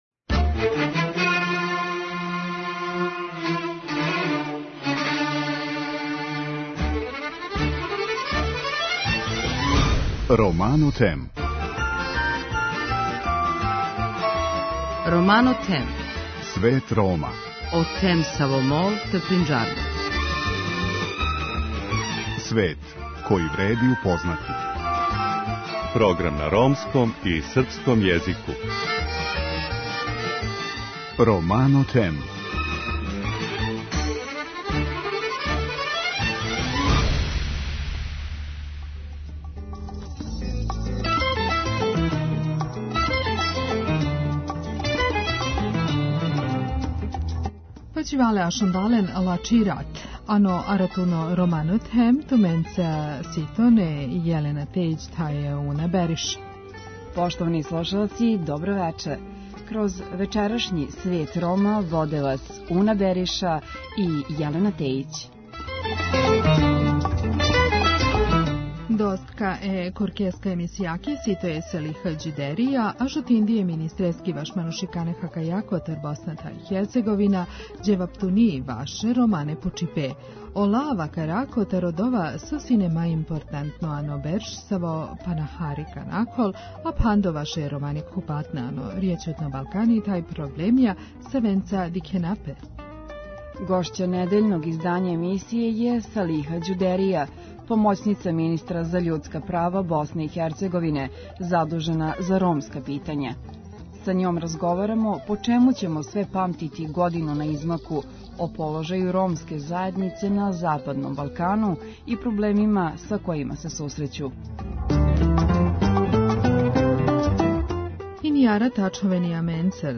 Гошћа емисије је Салиха Ђудерија, помоћница министра за људска права Босне и Херцеговине, задужена за ромска питања. Са њом ћемо разговарати по чему ћемо све памтити годину на измаку, какав је положај ромске заједнице на Западном Балкану и са каквим се све проблемима сусрећу.